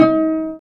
Index of /90_sSampleCDs/Roland LCDP13 String Sections/STR_Vcs Marc&Piz/STR_Vcs Pz.3 dry